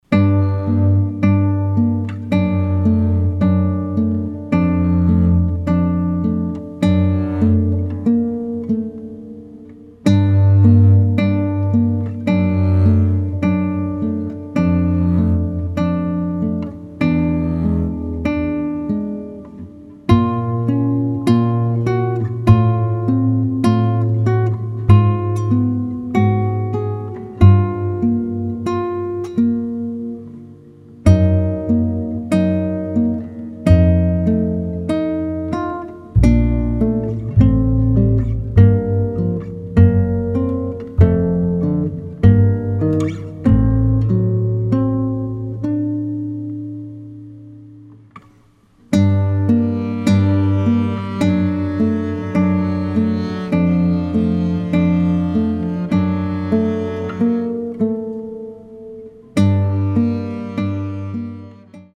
acoustic guitar
acoustic bass